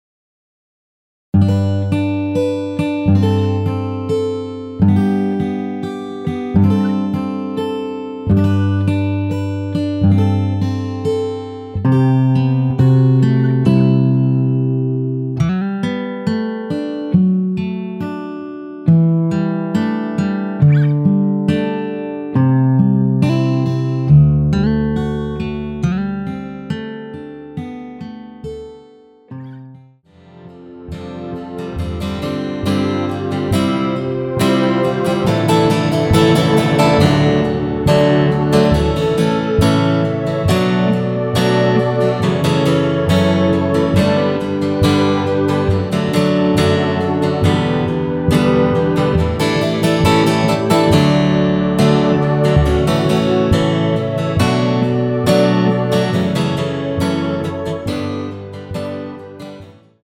(-2) 내린 MR 입니다.
◈ 곡명 옆 (-1)은 반음 내림, (+1)은 반음 올림 입니다.
앞부분30초, 뒷부분30초씩 편집해서 올려 드리고 있습니다.